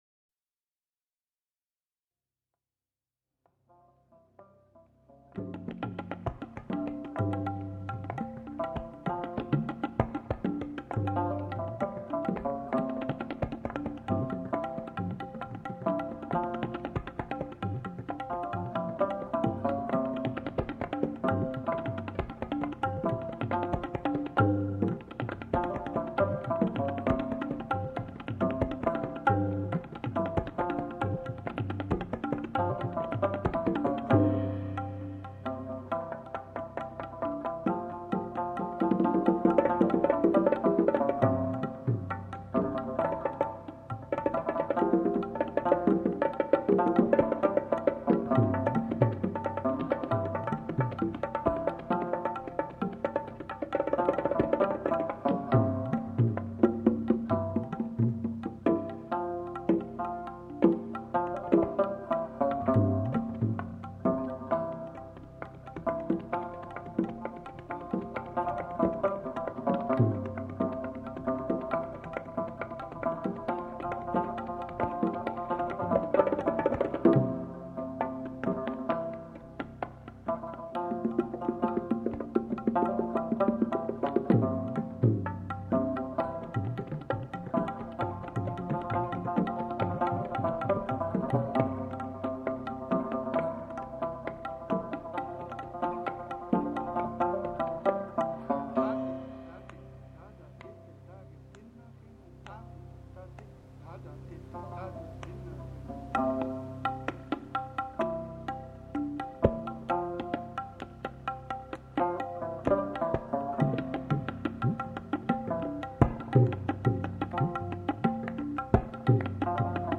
World Music
Percussion Duet